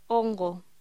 Locución: Hongo